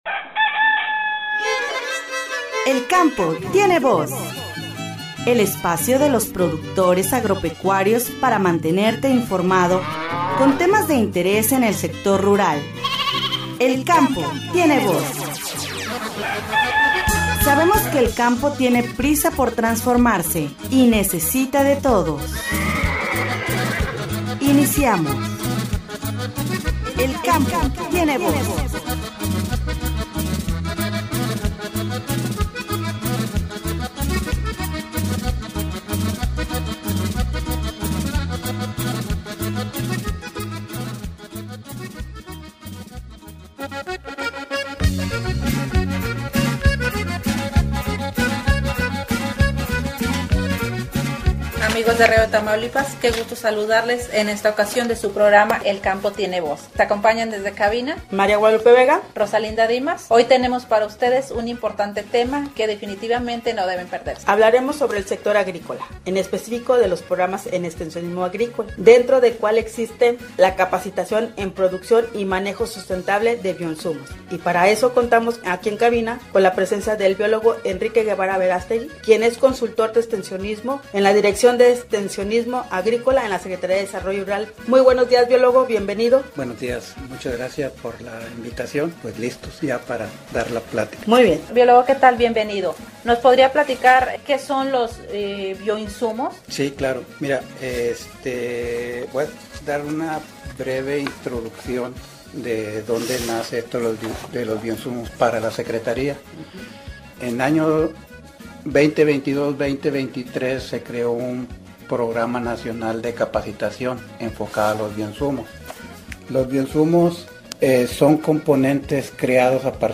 “El Campo Tiene Voz”, en esta ocasión se entrevistó al Biol.